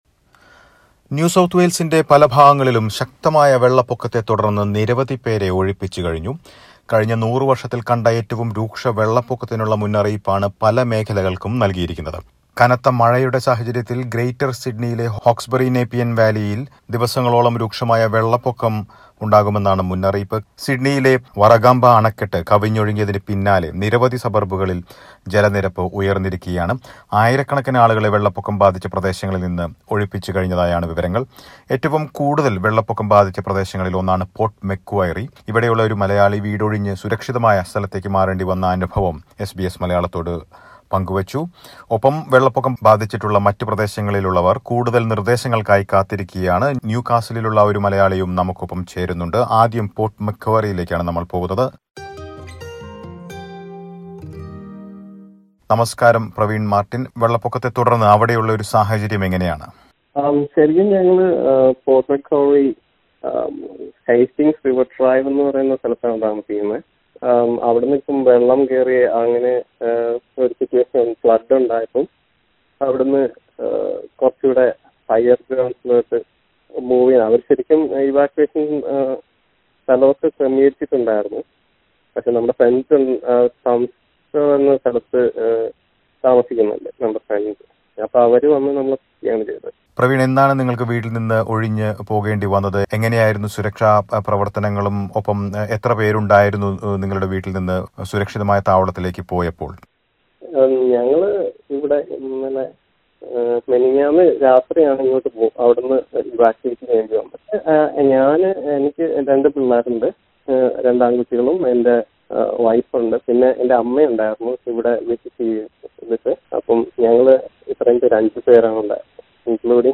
ഇതിൽ ഒരു മലയാളി എസ് ബി എസ് മലയാളത്തോട് സംസാരിക്കുന്നു. വെള്ളപൊക്കം ബാധിച്ചിട്ടുള്ള ന്യൂകാസിലിലുള്ള ഒരു മലയാളിയും വിവരങ്ങൾ പങ്ക് വക്കുന്നു.